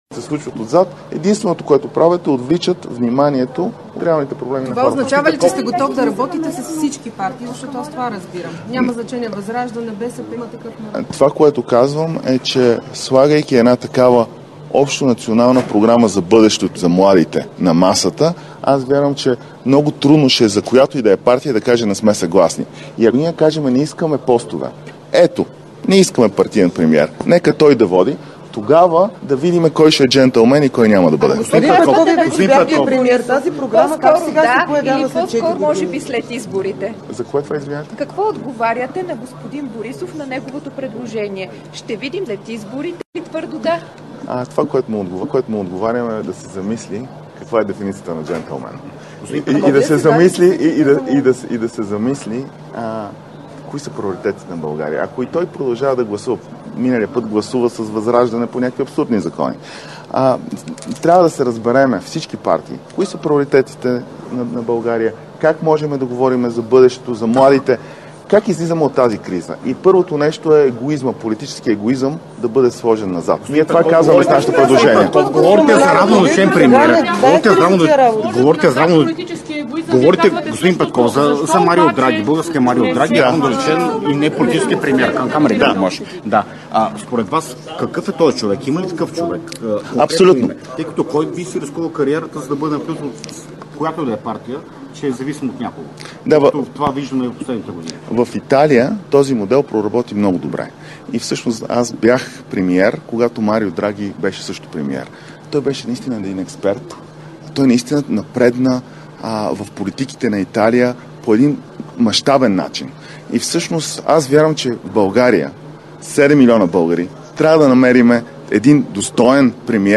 10.35 - Премиерът Димитър Главчев ще участва в тържествена церемония по награждаването на медалистите и класиралите се до 8-о място от Олимпийските и Параолимпийските игри в Париж 2024 г.. - директно от мястото на събитието (Гранитна зала на Министерски съвет)
Директно от мястото на събитието